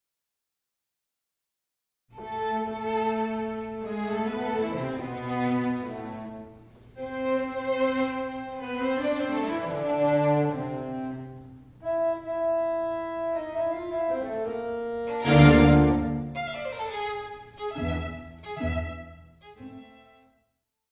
violoncello
harmonium
Music Category/Genre:  Classical Music